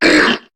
Cri de Parecool dans Pokémon HOME.